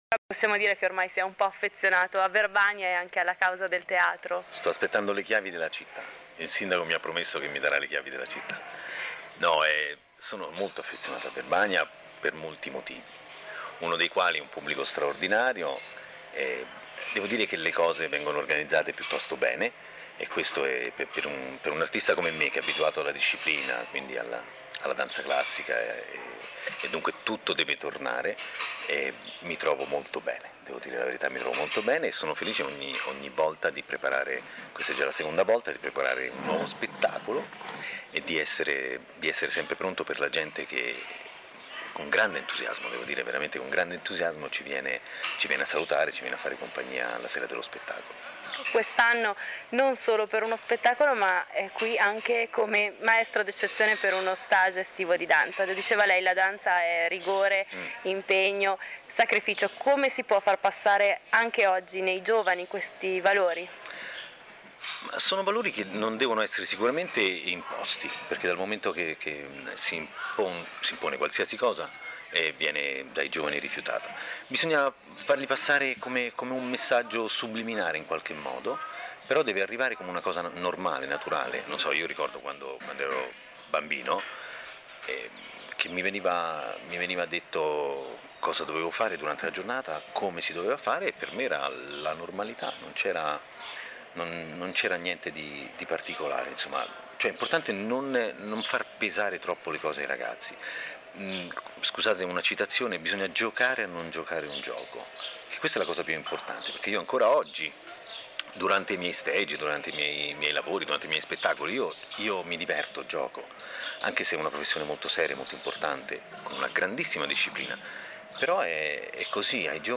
Venerdi 22 luglio 2011 intervista a Raffaele PAGANINI realizzata da Rvl la Radio